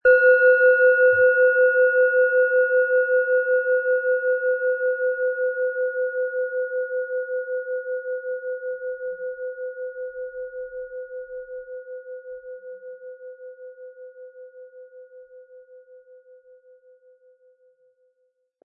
Planetenschale® Stärke Dein Selbstbewusstsein & Stärke Deine Lebenskraft mit Sonne, Ø 10,6 cm, 180-260 Gramm inkl. Klöppel
Um den Originalton der Schale anzuhören, gehen Sie bitte zu unserer Klangaufnahme unter dem Produktbild.
Der passende Klöppel ist kostenlos dabei, der Schlegel lässt die Klangschale harmonisch und wohltuend anklingen.
MaterialBronze